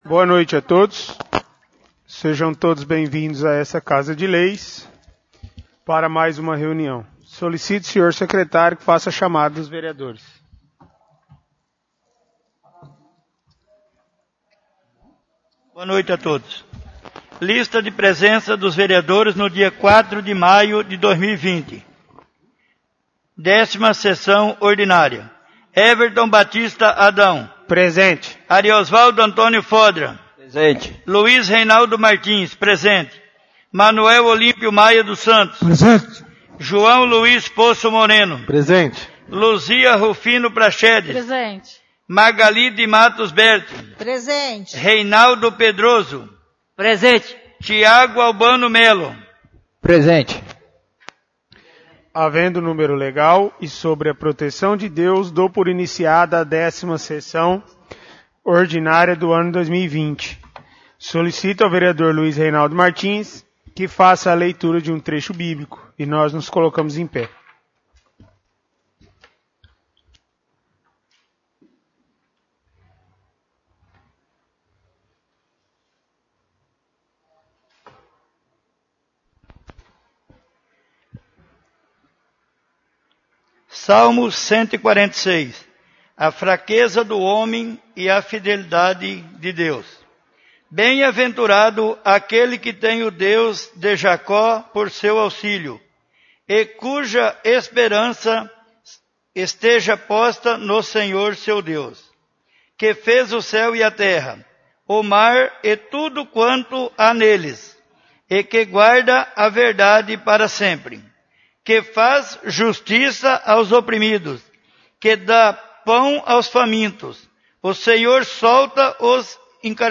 10° Sessão Ordinária